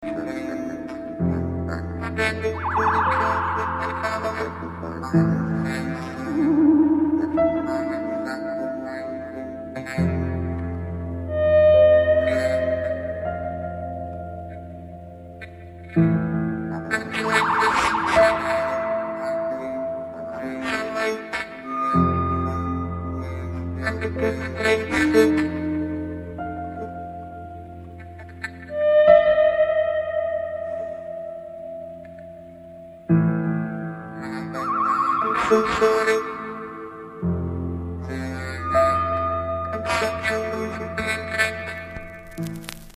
Disco Funk